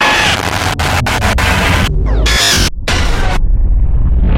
Звуки помех, глитча
Звук разрыва реальности в разрушенных воспоминаниях атакует резко